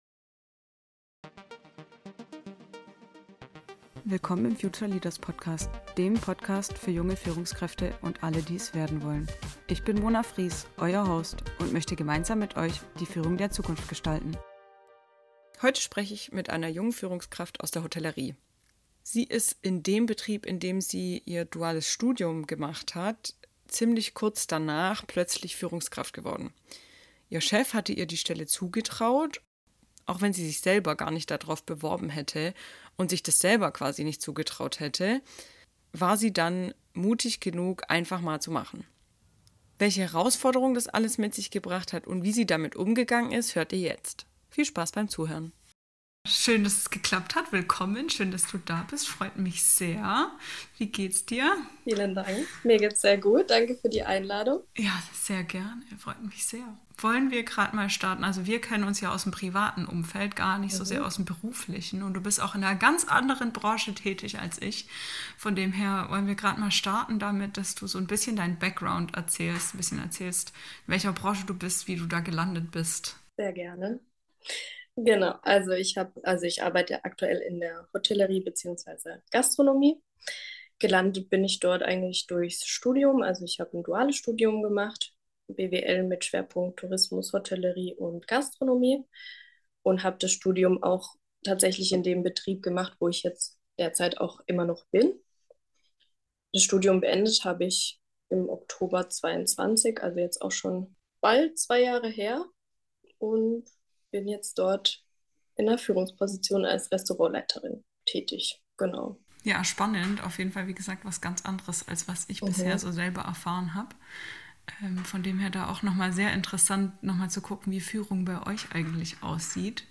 Im Interview mit einer Restaurantleiterin aus der Hotellerie geht es um ihre Aufgaben als Führungskraft, wie sie von der Studentin plötzlich zur Chefin im selben Umfeld wurde, was dabei ihre Herausforderungen waren, wie sie damit umgegangen ist und was sie sich dabei gewünscht hätte.